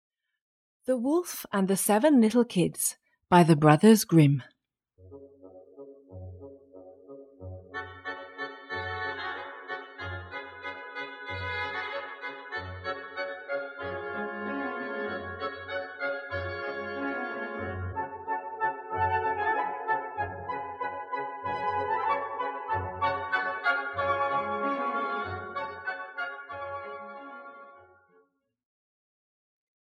The Wolf and the Seven Little Kids, a Fairy Tale (EN) audiokniha
Ukázka z knihy